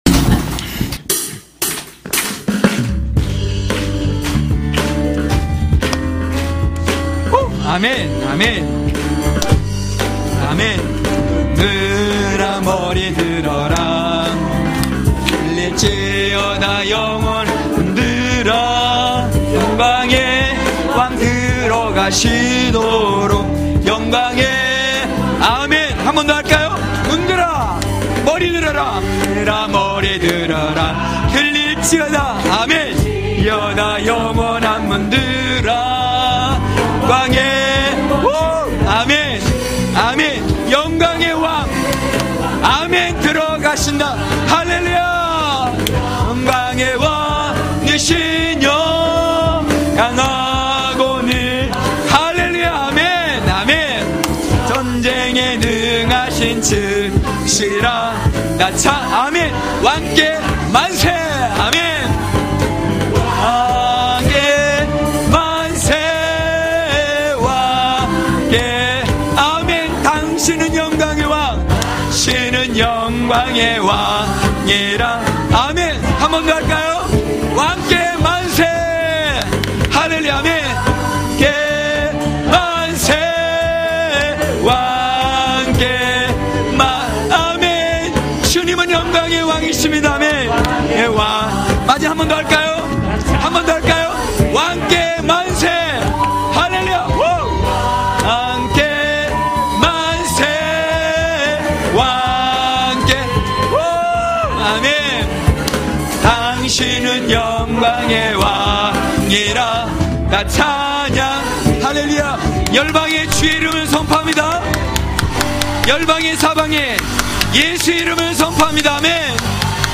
강해설교 - 9.다음세대를 위한 성벽(느5장5~13절).mp3